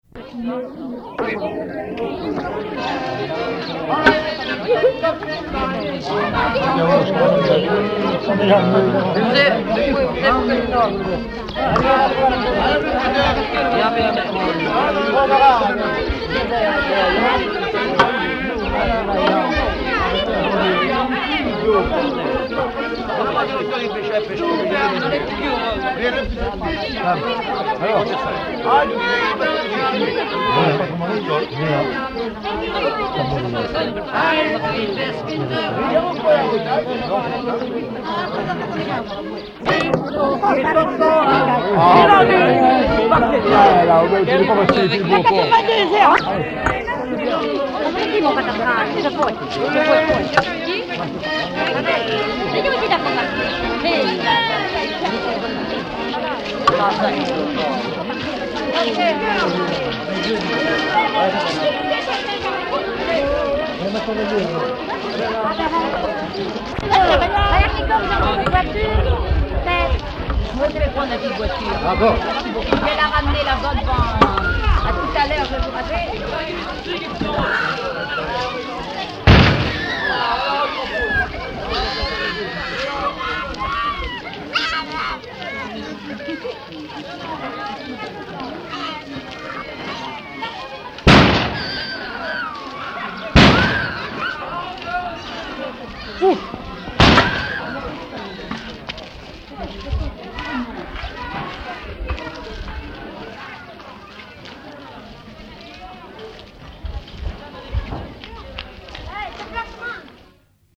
Ambiance sonore de la noce avec coup de fusil
Usage d'après l'analyste circonstance : fiançaille, noce ;